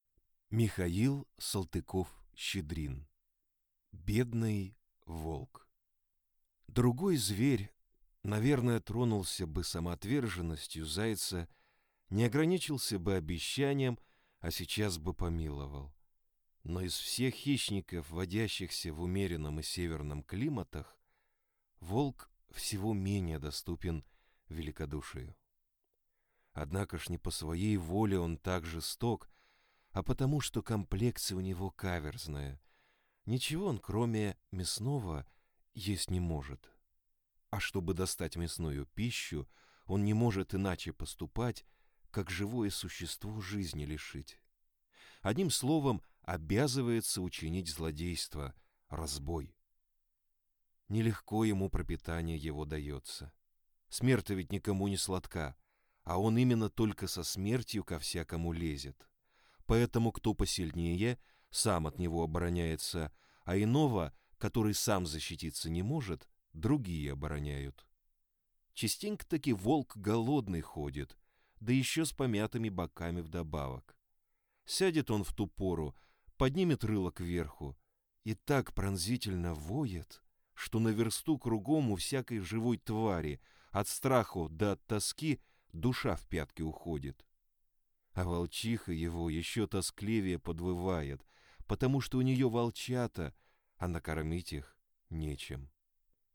Аудиокнига Бедный волк | Библиотека аудиокниг
Прослушать и бесплатно скачать фрагмент аудиокниги